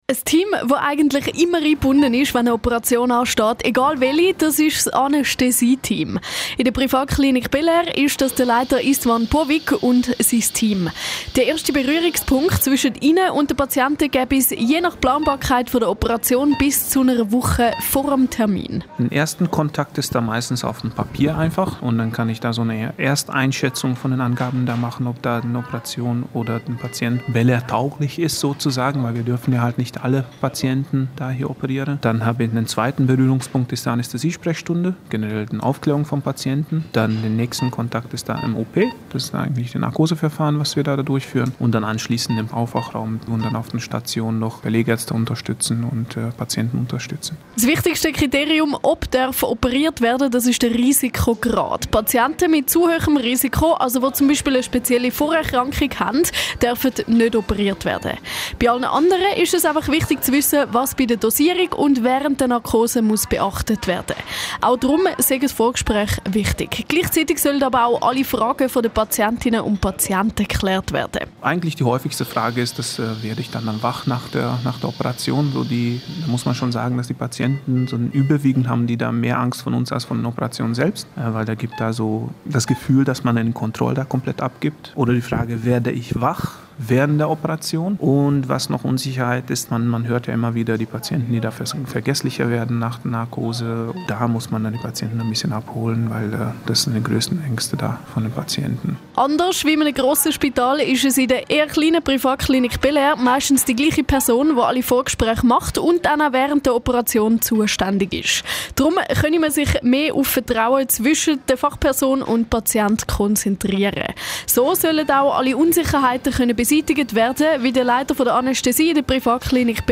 Patientinnen und Patienten erzählen von ihren Erfahrungen.